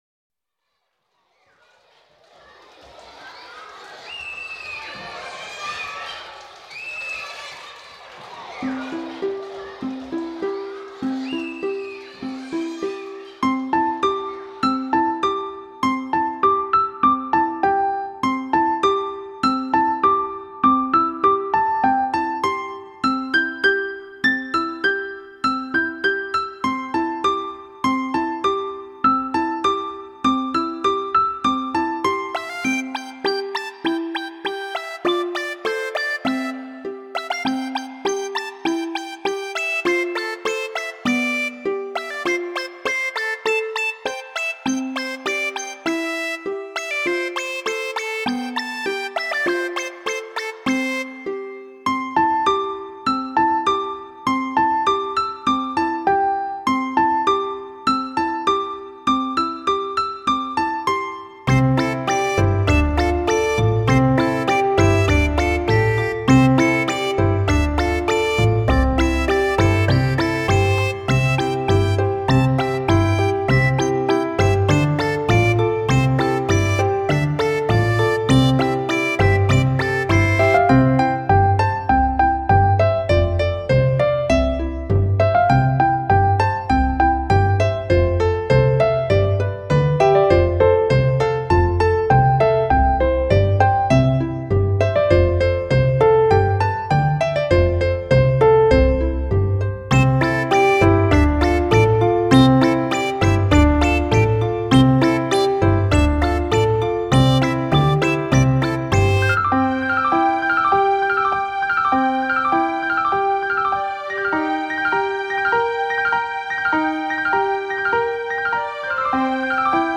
春天的童颜笑语、虫声鸟啼！